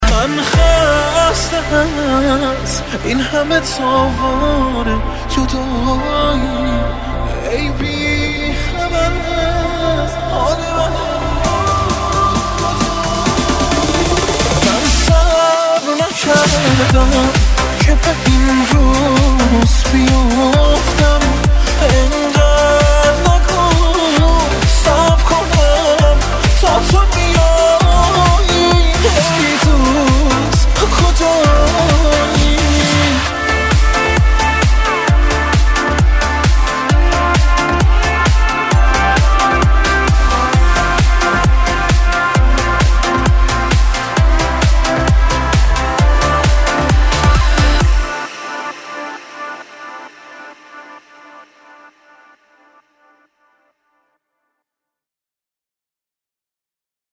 آهنگ موبایل احساسی و با کلام